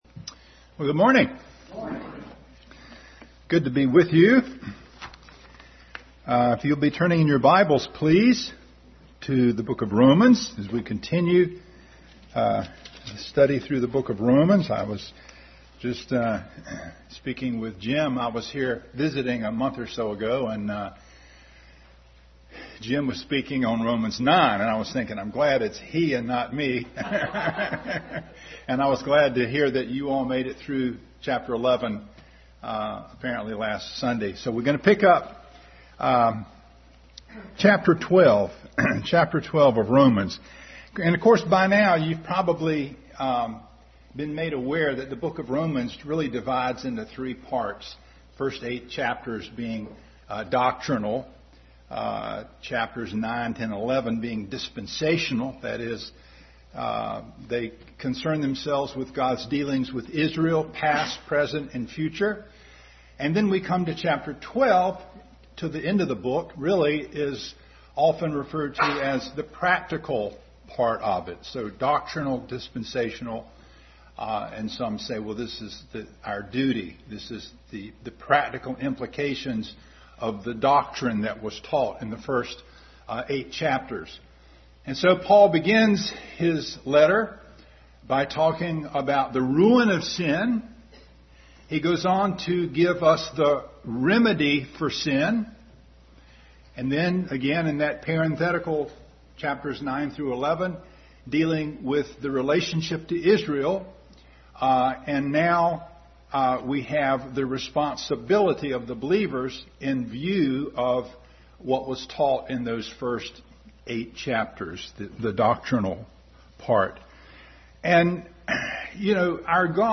Adult Sunday School continued study in Romans.